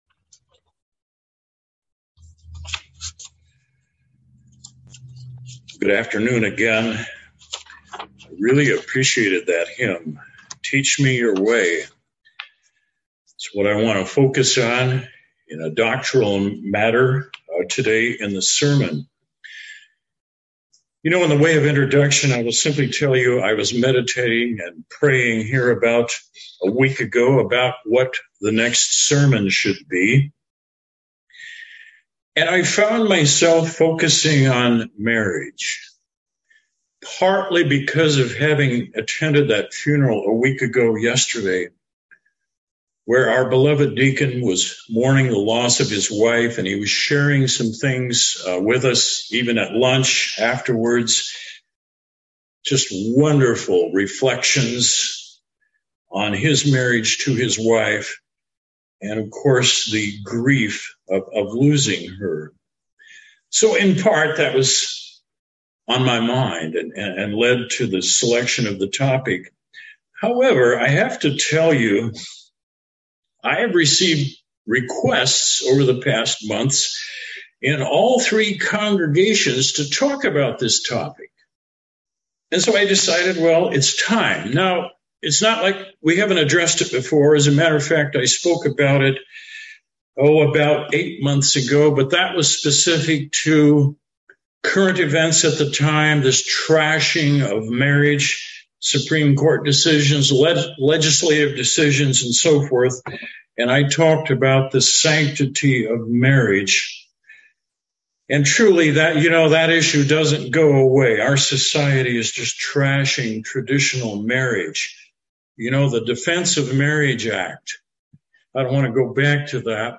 The bible gives us timeless principles for establishing good marriages. This sermon covers a portion of those points along with personal experience.